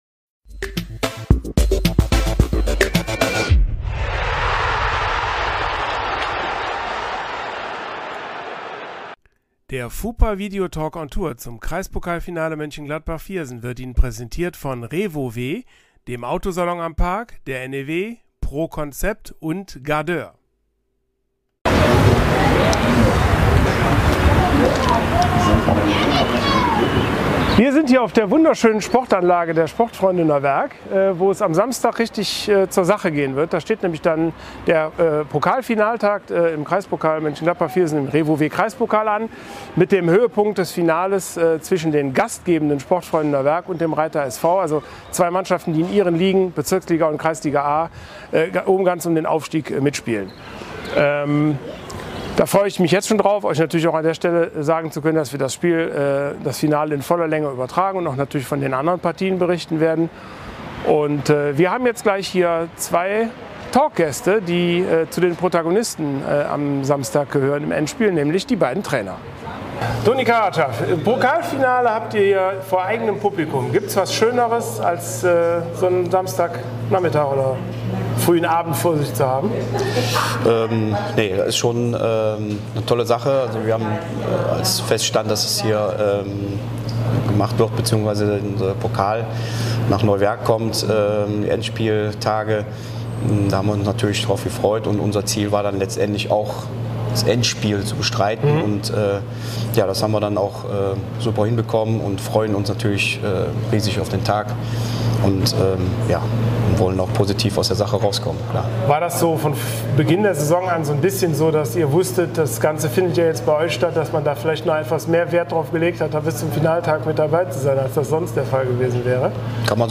So war es auch bei der Rückkehr des Talk-Formats, wir waren nämlich zu Gast bei den Sportfreunden Neuwerk. Hier gibt es die Audio-Spur der beiden Gespräche auf FuPalaver - dem Podcast von FuPa Niederrhein.